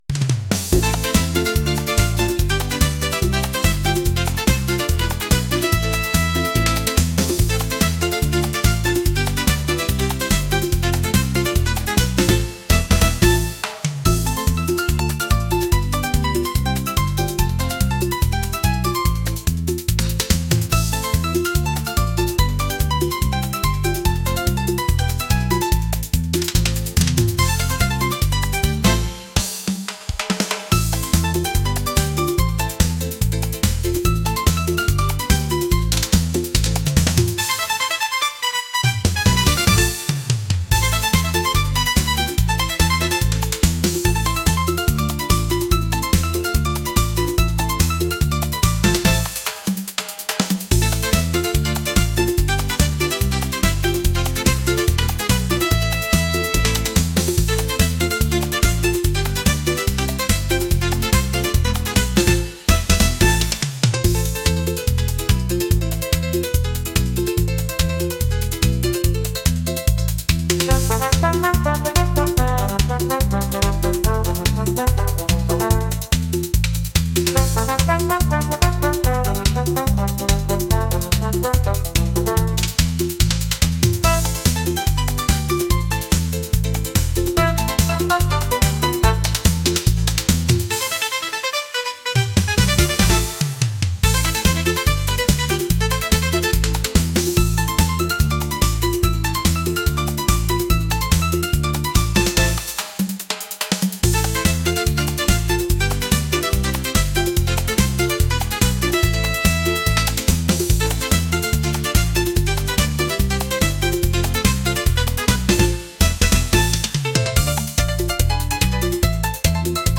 lively | energetic | latin | pop